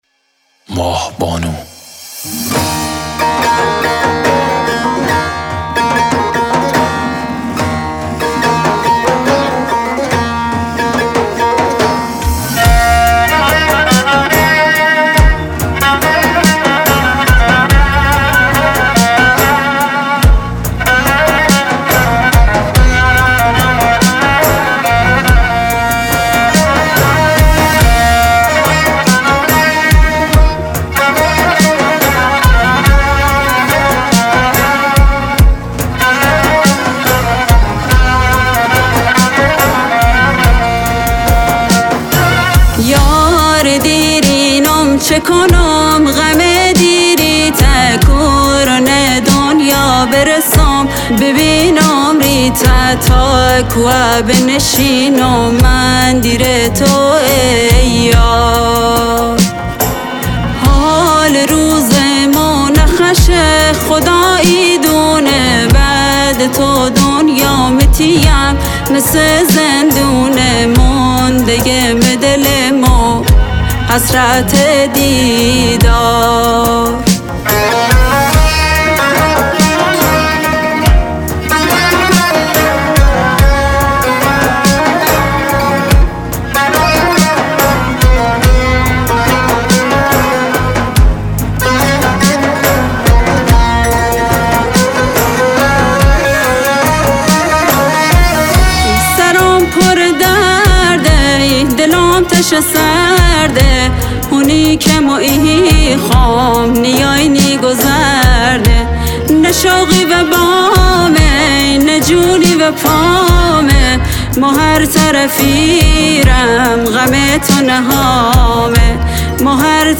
تار و کمانچه
نی، تنبک و دف